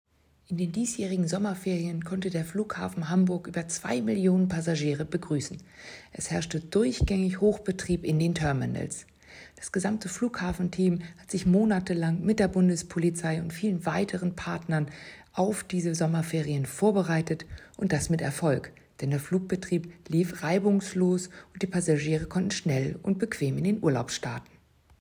O-Ton (Audio)